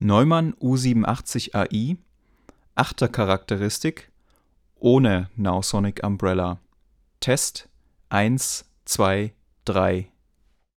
Die folgenden Aufnahmen sind mit unserem Neumann U 87 Ai entstanden, da dessen Sound am bekanntesten für alle Leser sein wird.
Dann ging es über ein Vovox-Kabel in einen der Mikrofonvorverstärker des RME Fireface 800.
Man muss nicht einmal die Ohren spitzen, um die durch den Nowsonic Umbrella bewirkte Halldämmung in Aufnahmen mit Kugelcharakteristik zu hören. Naturgemäß fällt der Effekt mit Nieren- oder Achtercharakteristik eher subtil aus.
Wer hätte gedacht, dass unser akustisch vernachlässigter Büroraum (okay, immerhin liegt Teppichboden aus) unter Zuhilfenahme dieses Micscreens deutlich trockener zu klingen vermag?